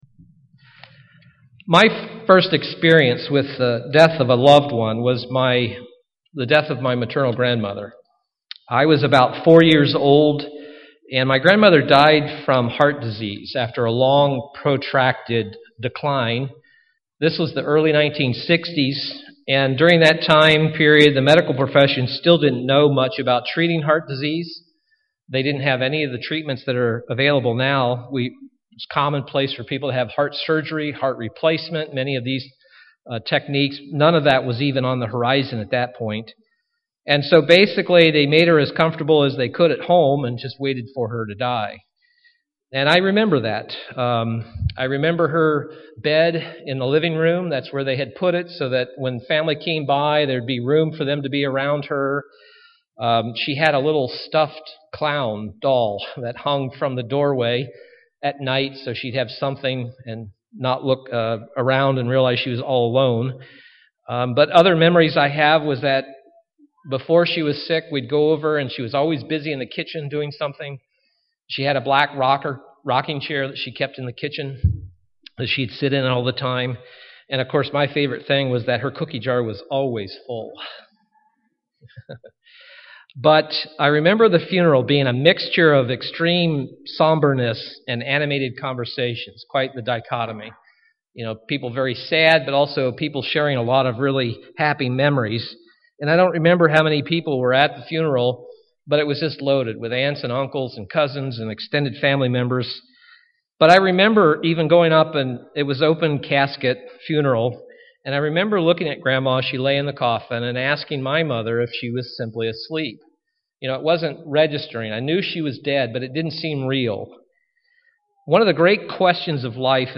Given in Milwaukee, WI
Print The truth of the resurrections from God's Word UCG Sermon Studying the bible?